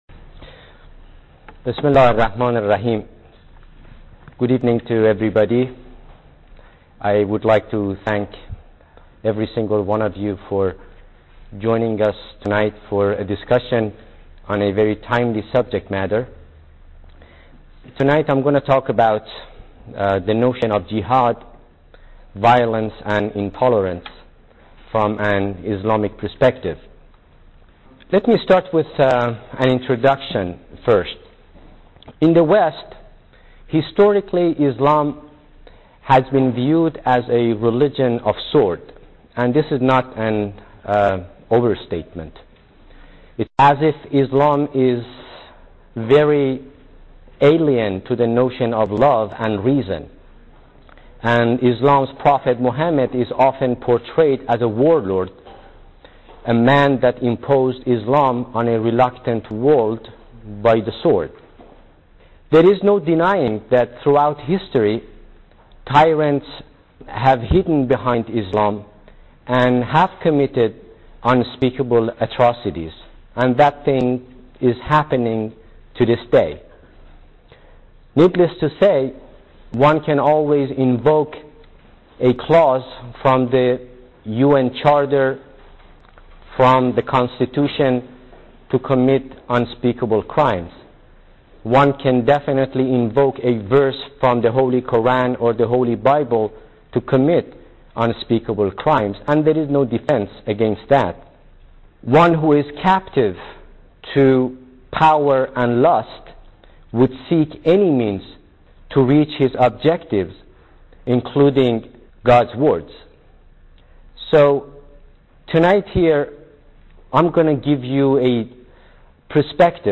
Lecture title: America’s Challenges and Opportunities